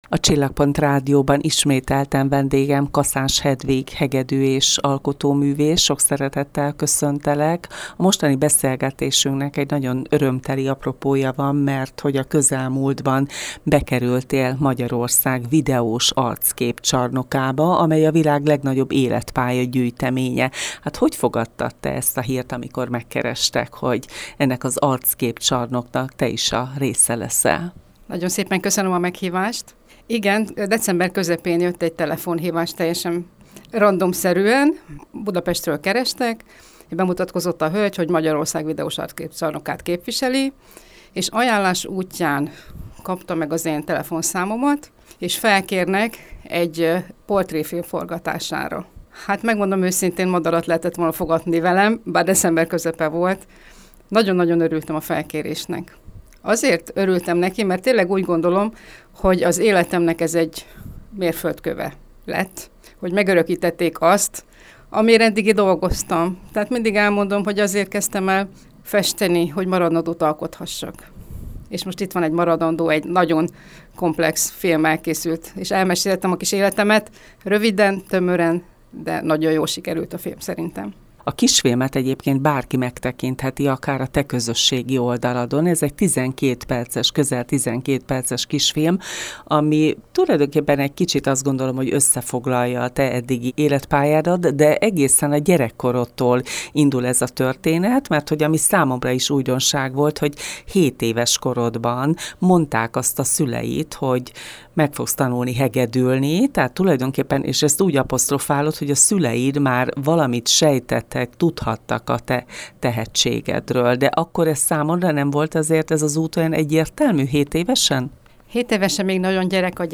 Ez adta az apropóját egy újabb beszélgetésnek a Csillagpont Rádióban.